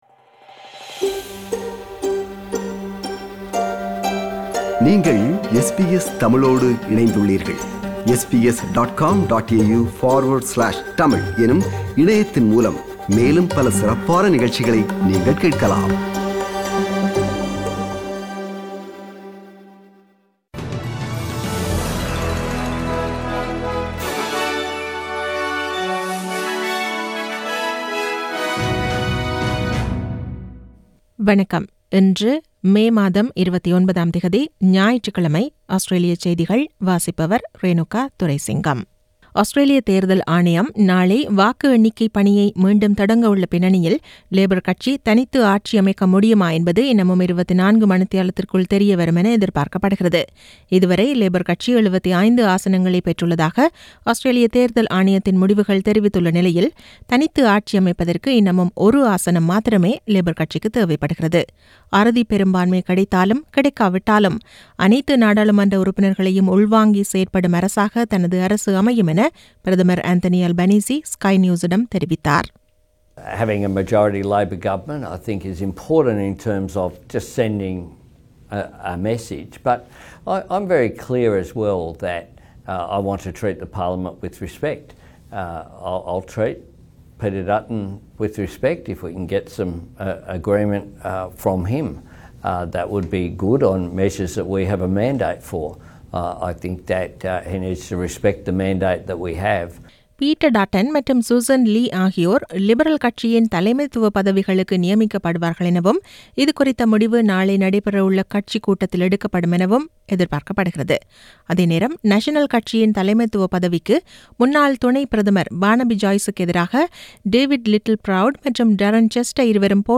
Australian news bulletin forSunday 29 May 2022.